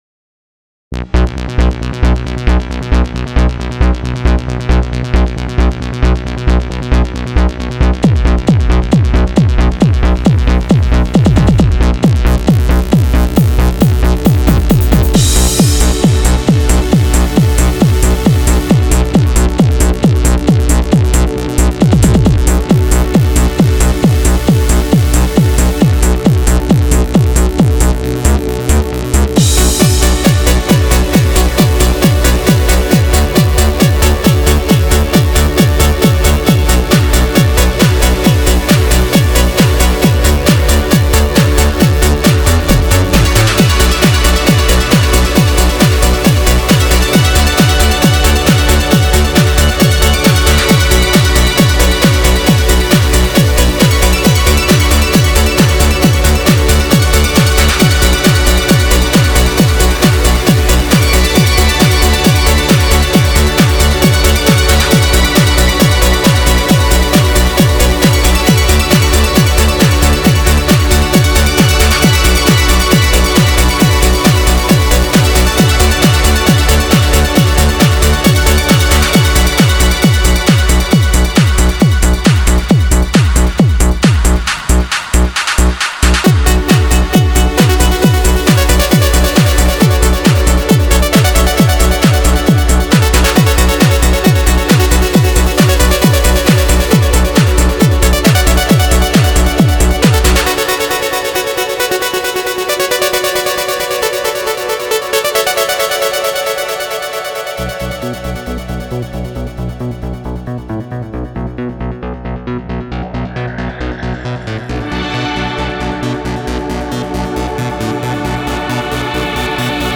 new sound of discomusic and space